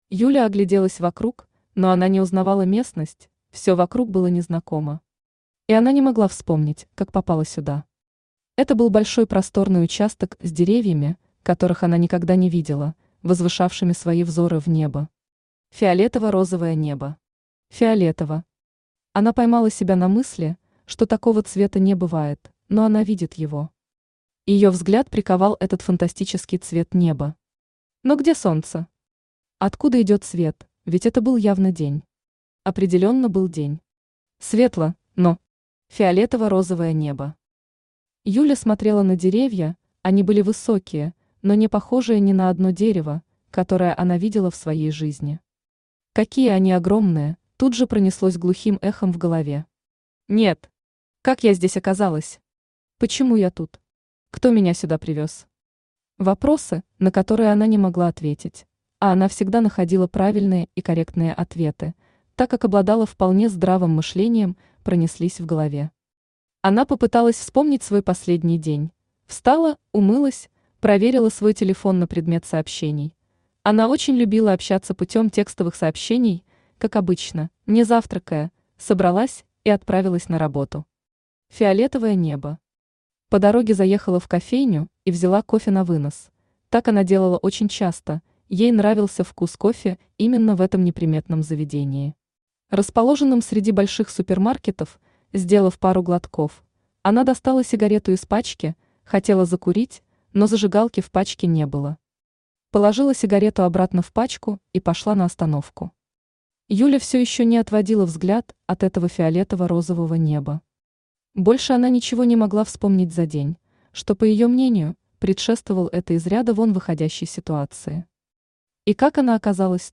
Аудиокнига Фиолетовое небо | Библиотека аудиокниг
Aудиокнига Фиолетовое небо Автор Son of Odin Читает аудиокнигу Авточтец ЛитРес.